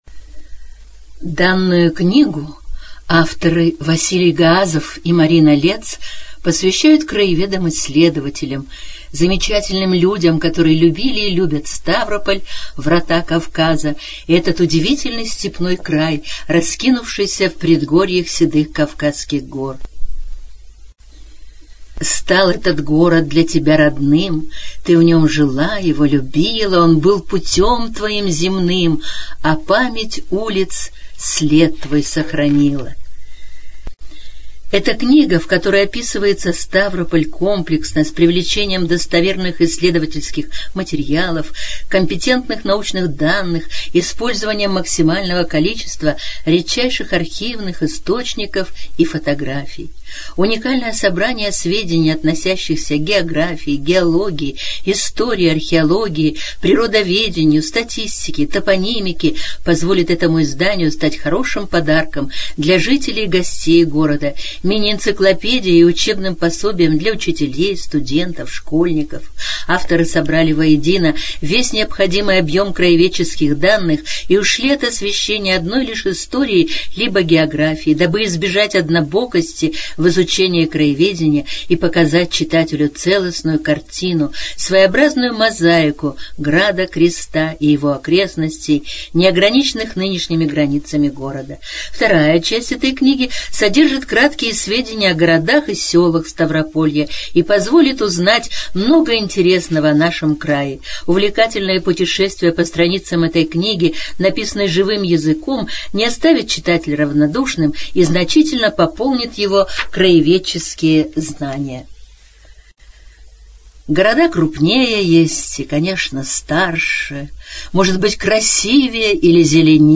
ЖанрАудиоэкскурсии и краеведение
Студия звукозаписиСтавропольская краевая библиотека для слепых и слабовидящих